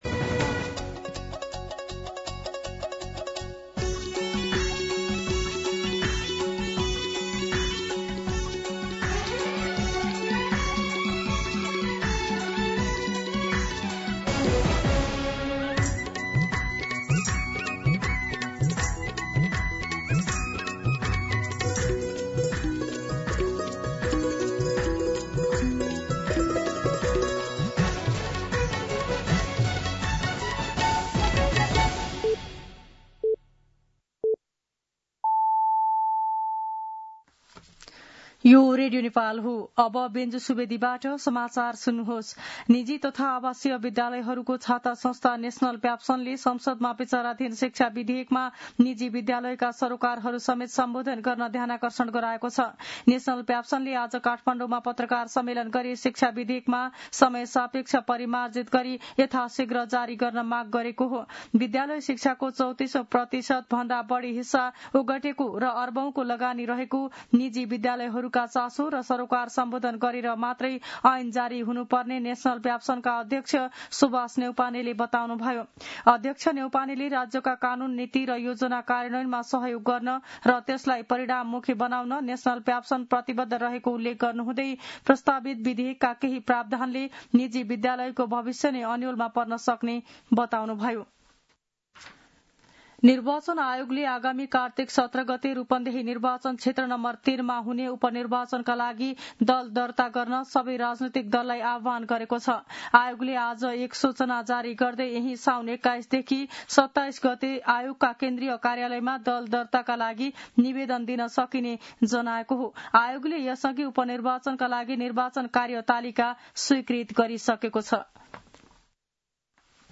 मध्यान्ह १२ बजेको नेपाली समाचार : १६ साउन , २०८२
12-pm-Nepali-News.mp3